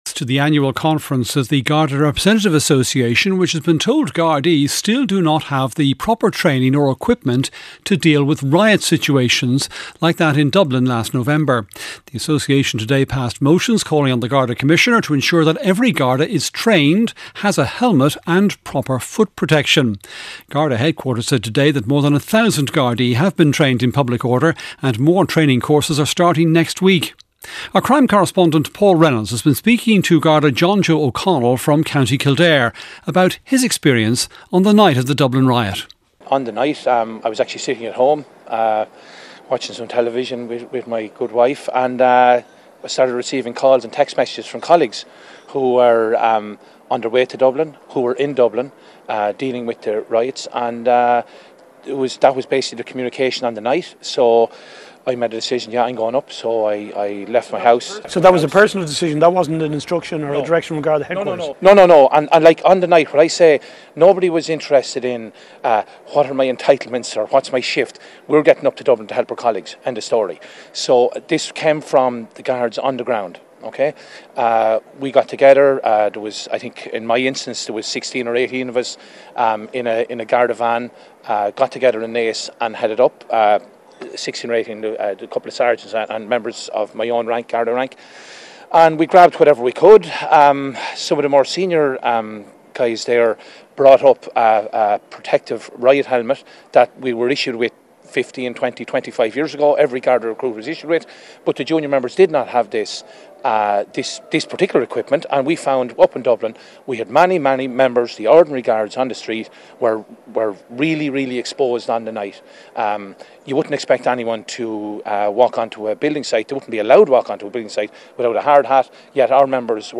8:35am Sports News - 22.05.2024